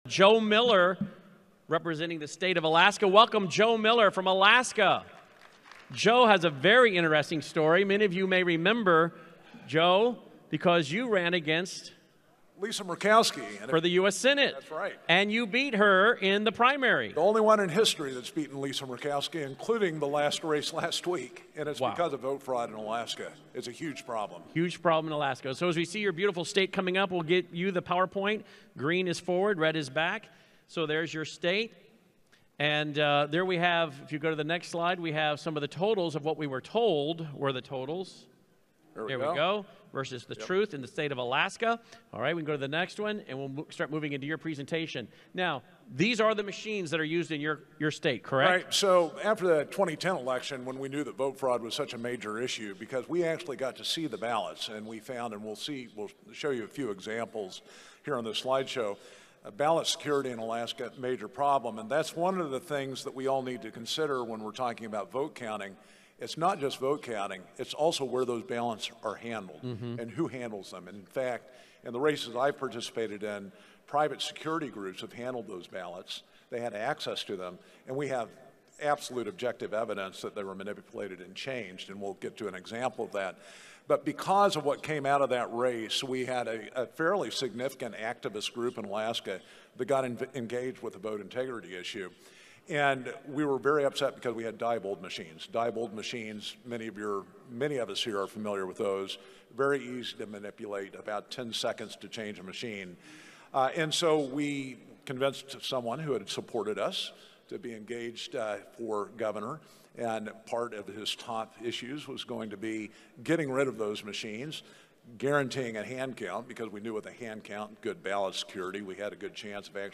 2022 AK Moment of Truth Summit State of the States Presentation audio – Cause of America